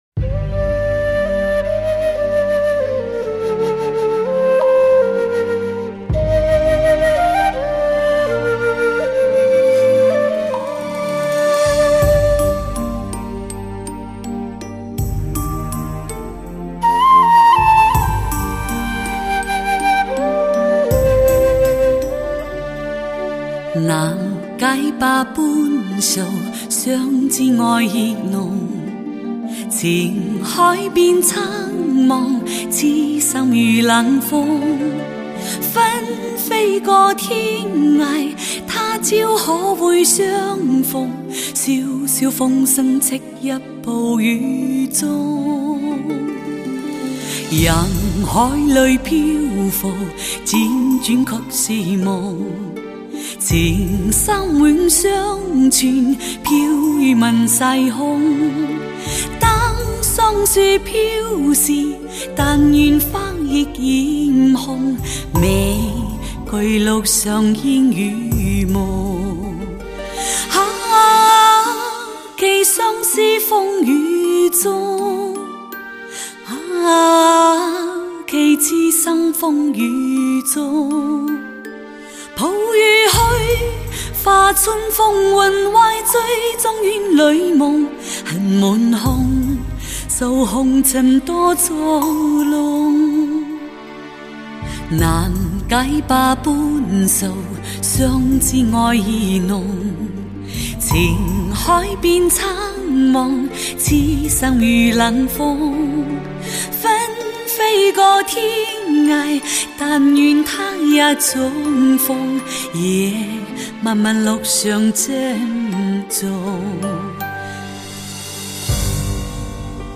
发烧天后级别的完美女中音
用黑黑的大耳聽即可 發燒的歌聲 中低音 謝謝即往 周末深夜連發兩帖 辛苦鳥
全新的“超细腻”演绎风格，磁性的嗓音，完美中增添感性
完美无缺的磁性声音 真舒服